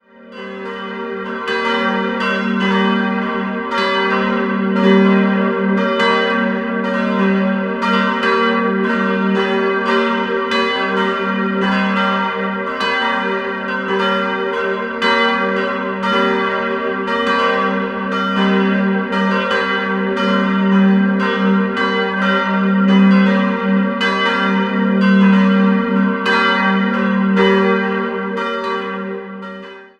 3-stimmiges Geläut: g'-b'-c'' Die Glocken wurden 1967 von Friedrich Wilhelm Schilling in Heidelberg gegossen.